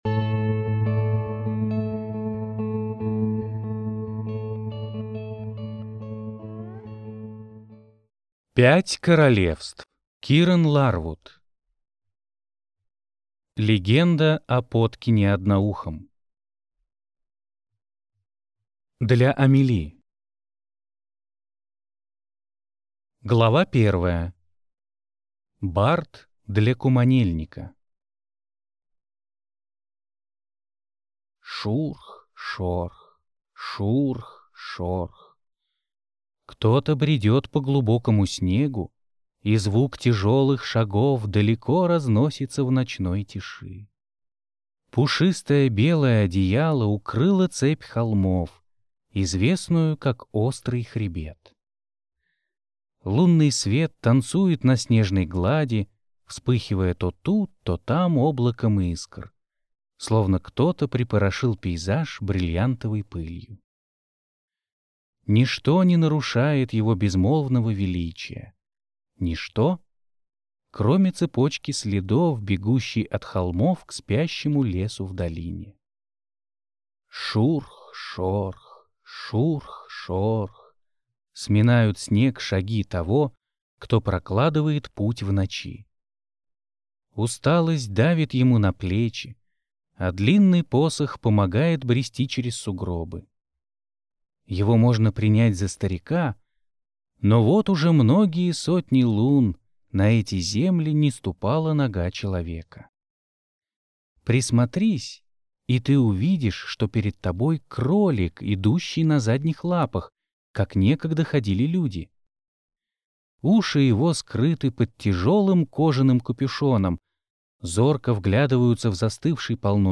Аудиокнига Легенда о Подкине Одноухом | Библиотека аудиокниг